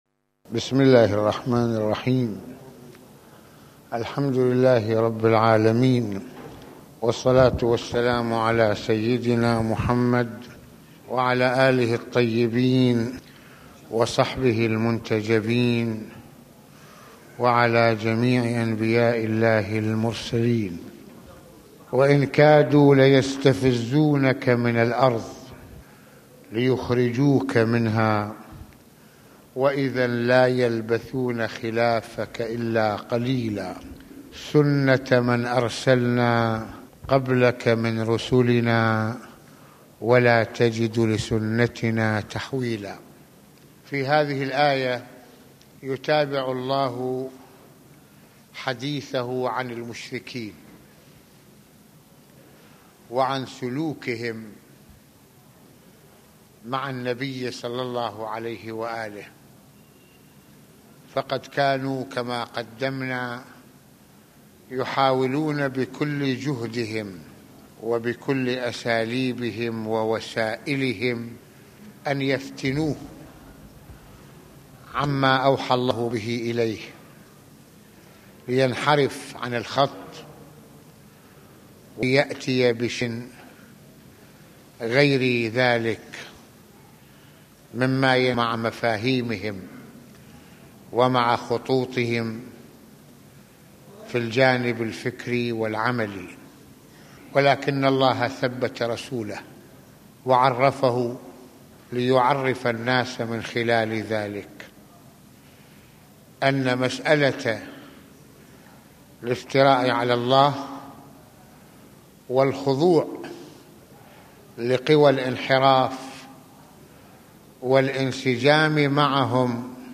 - يتناول المرجع السيد محمد حسين فضل الله (رض) في هذه المحاضرة سلوك المشركين لدفع الرسول إلى خطهم ، ومواجهته لإفتراءاتهم وإغراءاتهم بالثبات ، وما مارسه من أسلوب للدعوة بحيث فتحت القلوب والعقول على الحق ، وفي ذلك الدروس للعاملين في سبيل الله حول كيفية التأسيس للرسالة إضافة إلى عناوين أخرى متصلة ...